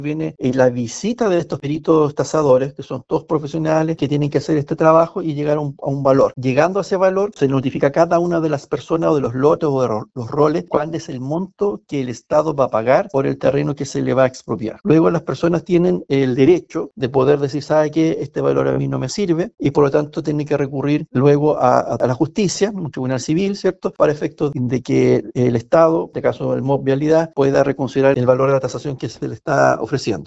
Así lo explicó el representante del MOP en la zona, Juan Alvarado.